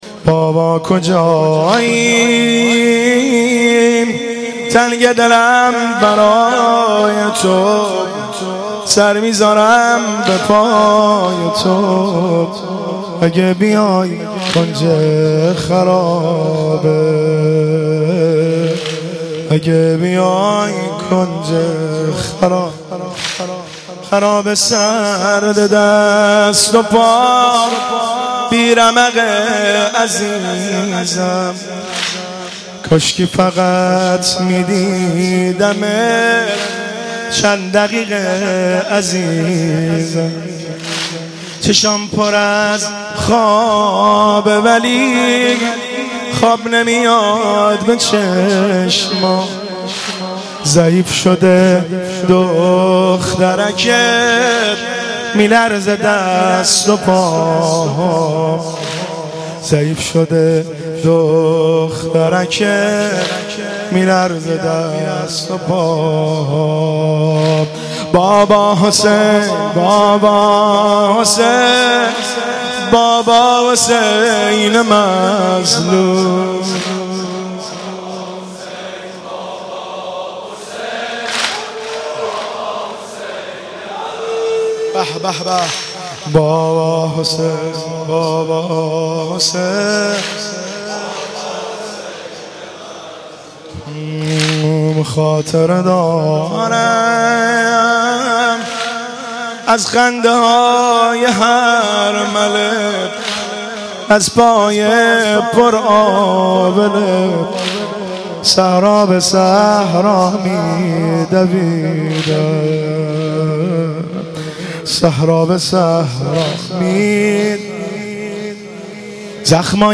مداحی بابا کجائی(واحد)
شب دوم محرم 1396
هیئت غریب مدینه امیرکلا